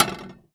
R - Foley 34.wav